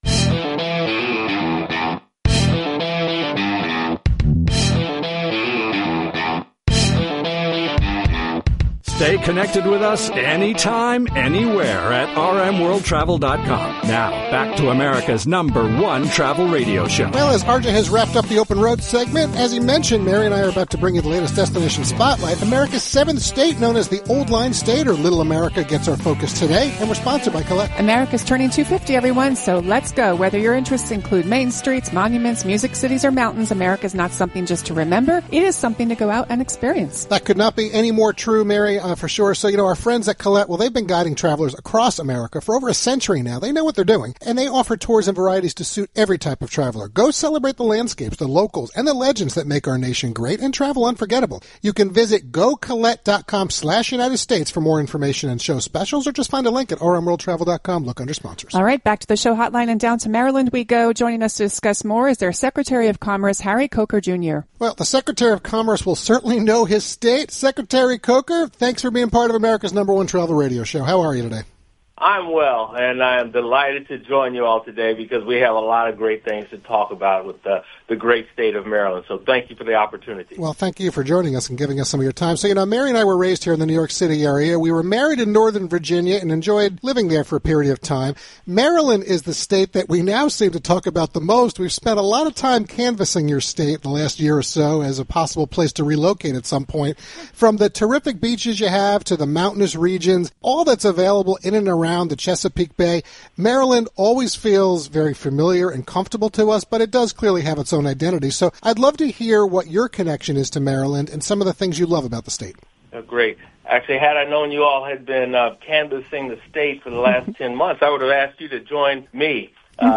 Harry Coker, Jr., Secretary of Commerce
The “Old Line State” or “Little America” happened during our live national broadcast of America’s #1 Travel Radio Show on December 6th.